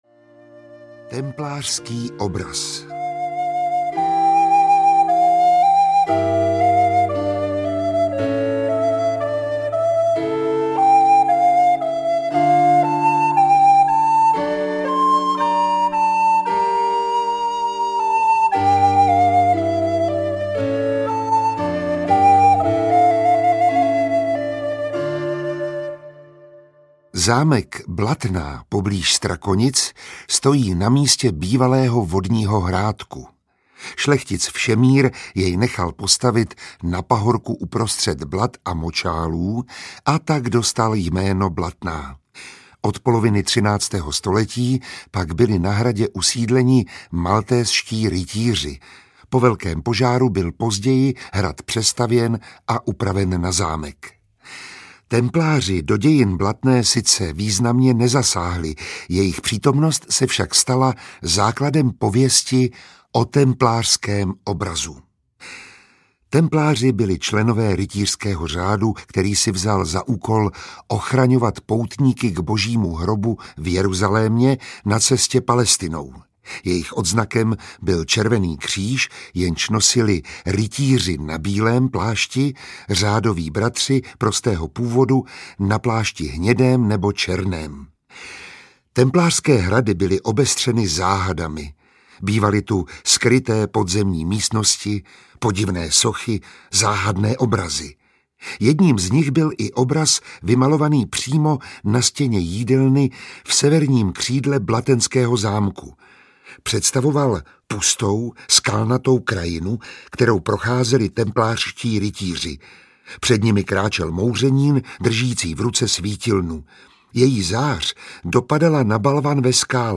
30 pověstí o městech, hradech a zámcích audiokniha
Ukázka z knihy
• InterpretMiroslav Táborský